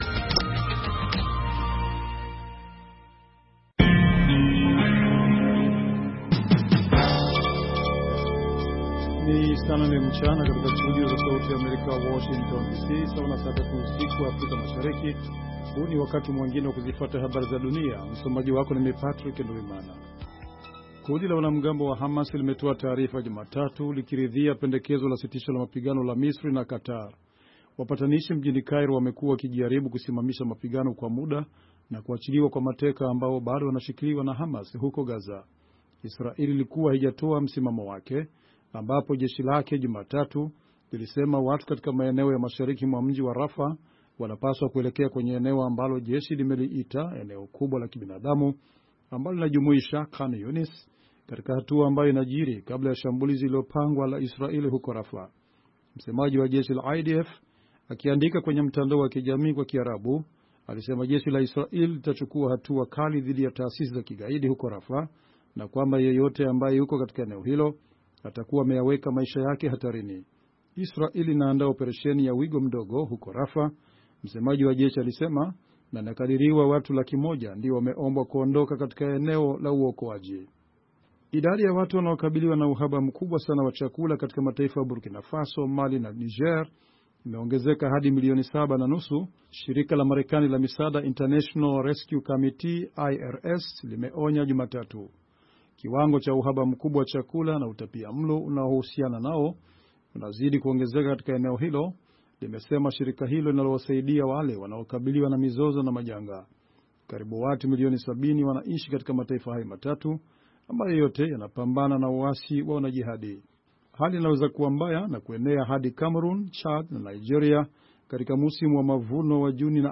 Kwa Undani ni matangazo ya dakika 25 yanayochambua habari kwa undani zaidi na kumpa msikilizaji maelezo ya kina kuliko ilivyo kawaida kuhusu tukio au swala lililojitokeza katika habari.